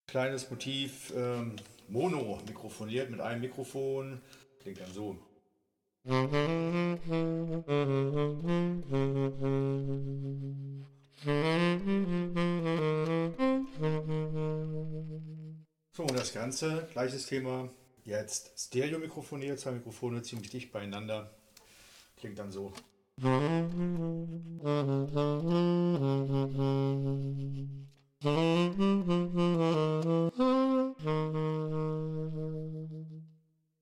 ) Anhänge MonoStereo.mp3 MonoStereo.mp3 610 KB · Aufrufe: 176